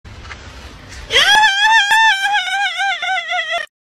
Goofy Ahh Laugh Sound - Botón de Efecto Sonoro